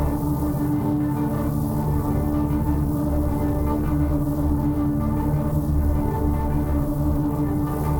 Index of /musicradar/dystopian-drone-samples/Tempo Loops/90bpm
DD_TempoDroneC_90-D.wav